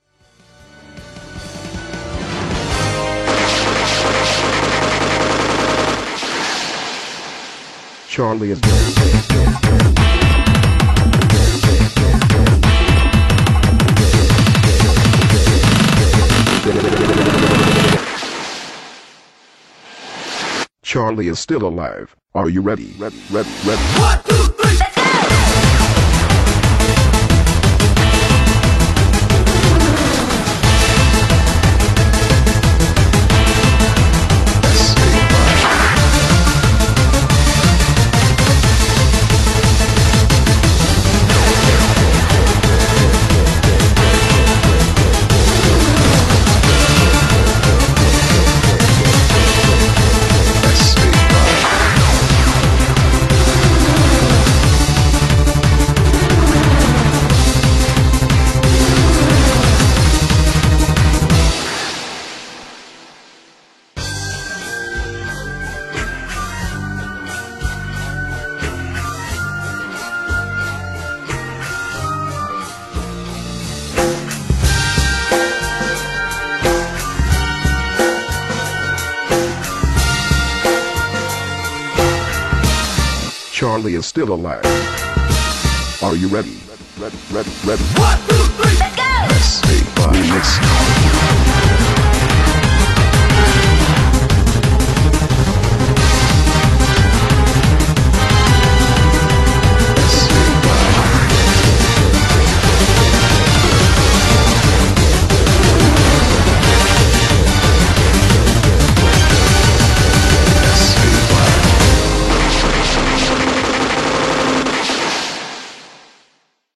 BPM90-180
Audio QualityMusic Cut